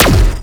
Zapper_3p_02.wav